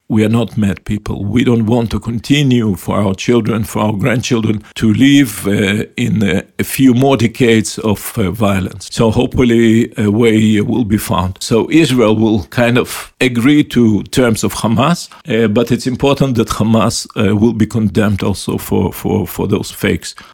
O svemu smo u Intervjuu tjedna Media servisa razgovarali s izraelskim veleposlanikom u Hrvatskoj Garyjem Korenom koji je poručio: "Mi nismo ludi ljudi, ne želimo još desetljeća nasilja; lideri trebaju glasno reći Hamasu da je dosta!"